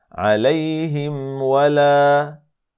Det ska uttalas på följande sätt: